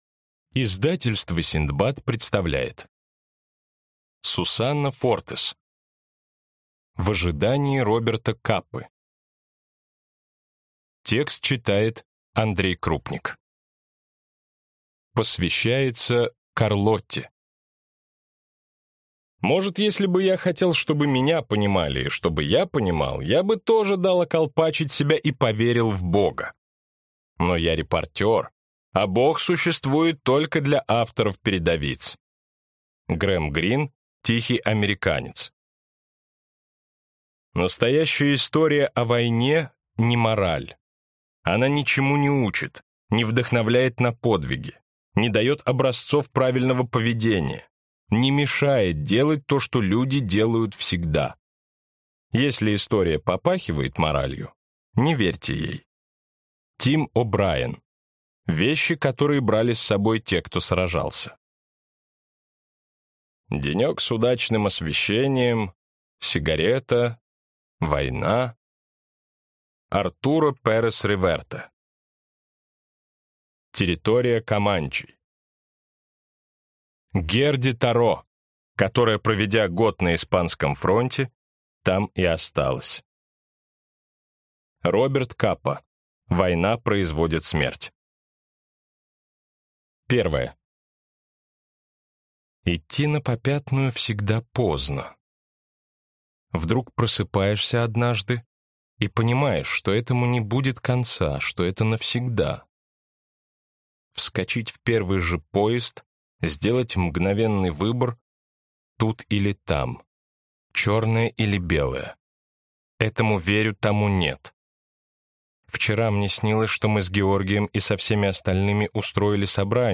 Аудиокнига В ожидании Роберта Капы | Библиотека аудиокниг